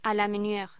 Locución: A la menier
voz